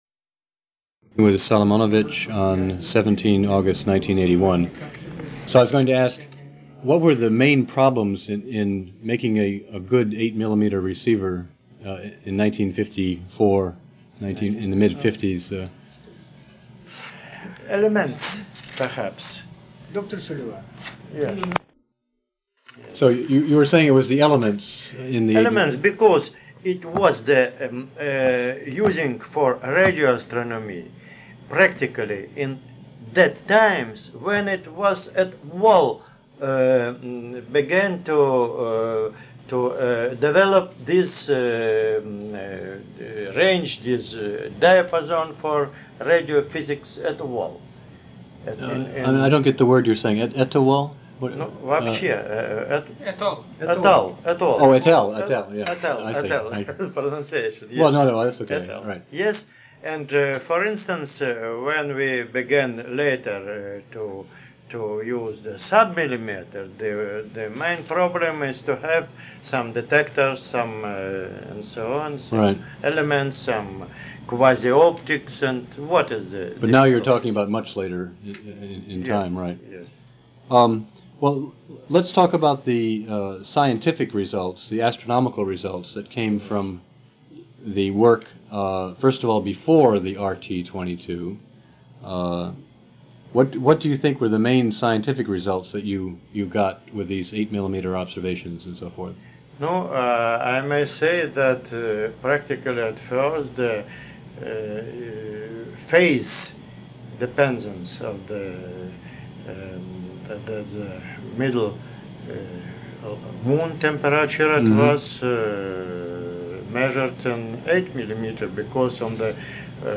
Oral History
Audio cassette tape